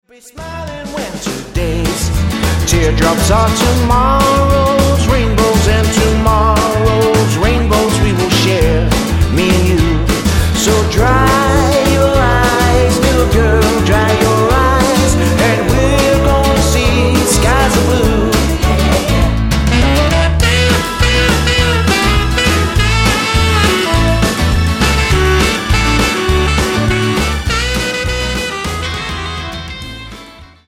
Tonart:A-Bb Multifile (kein Sofortdownload.
Die besten Playbacks Instrumentals und Karaoke Versionen .